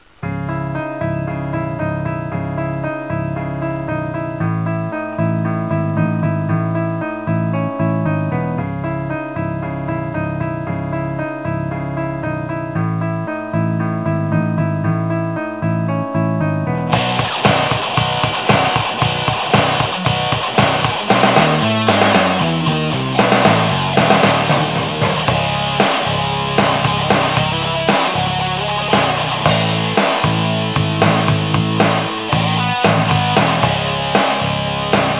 hardrock.wav